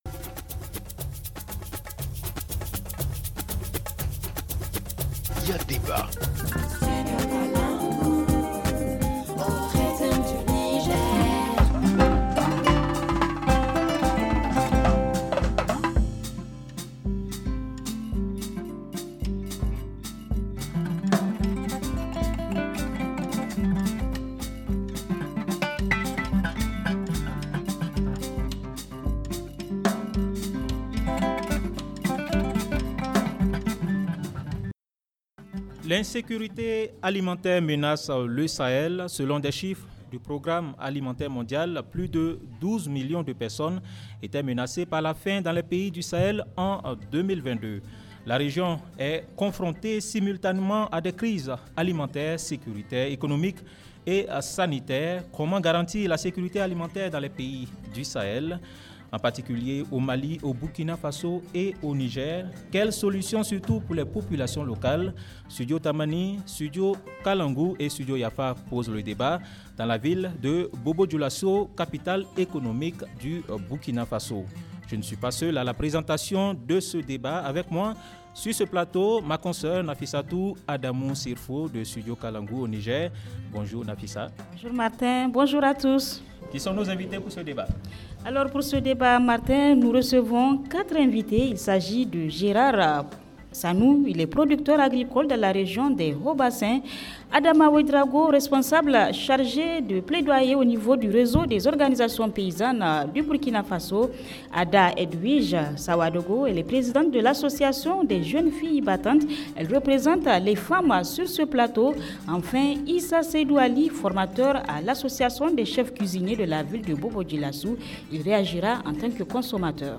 Comment garantir la sécurité alimentaire dans les pays du sahel en particulier au Mali ,au Burkina Faso et Niger ? Quelles solutions surtout pour les populations locales, Studio Tamani, Studio Kalangou et Studio Yafa posent le débat dans la ville de Bobo-Dioulasso, la capitale économique du Burkina Faso.